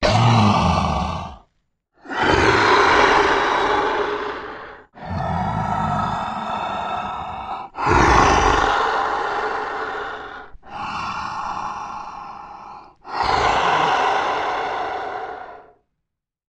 Звуки страшные, жуткие
Звук дыхания чудища